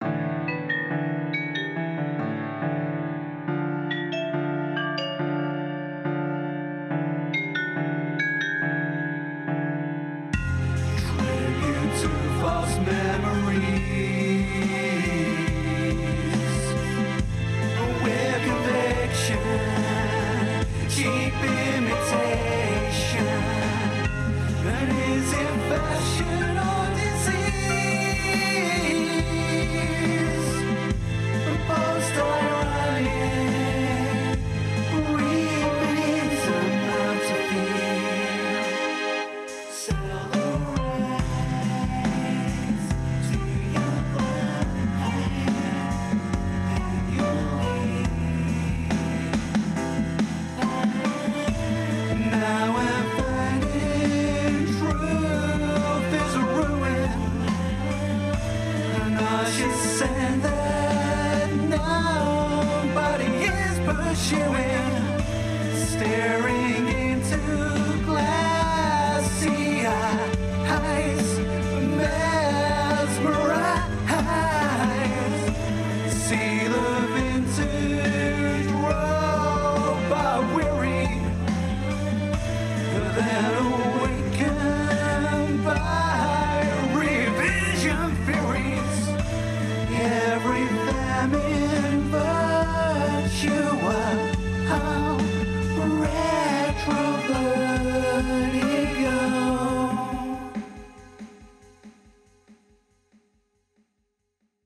additional harmonies
cover